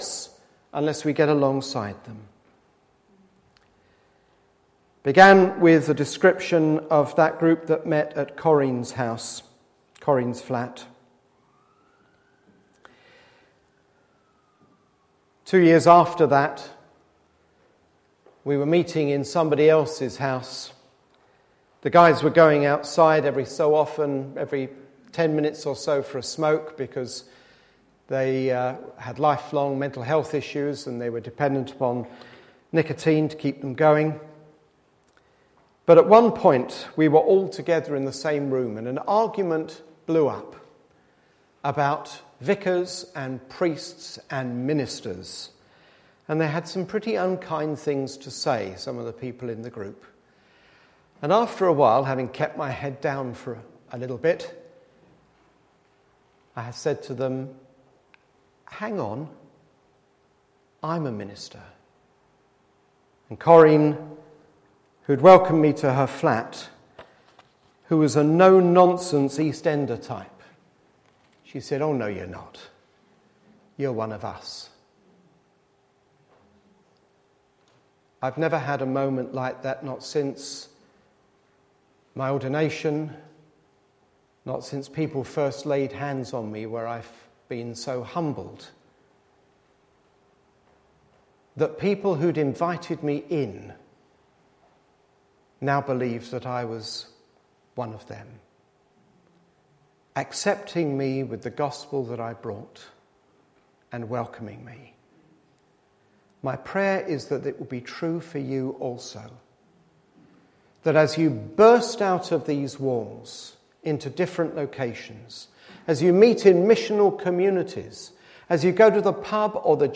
A message from the series "Mission."